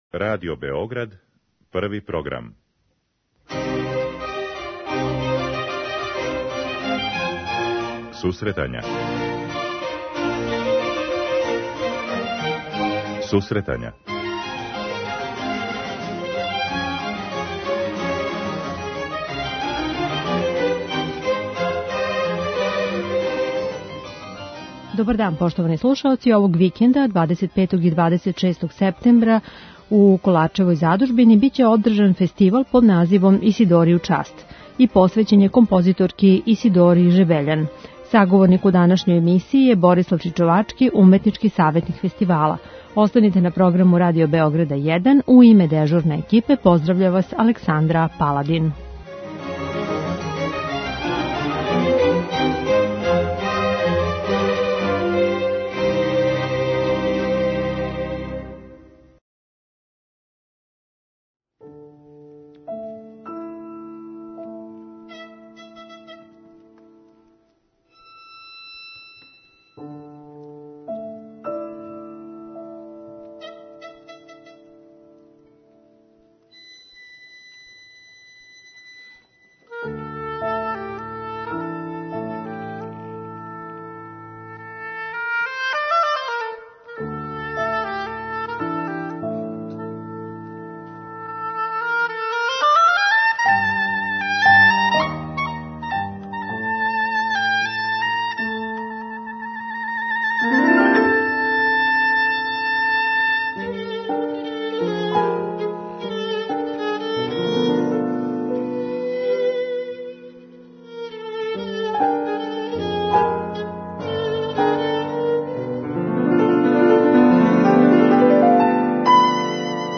Емисија за оне који воле уметничку музику.
Током емисије слушаоци ће бити у прилици да чују нека од најзначајнијих дела Исидоре Жебељан, међу којима су "Игра дрвених штапова", "Коњи Светог Марка", "Три козја увета" за обоу виолину и клавир и "Нове Ладине песме".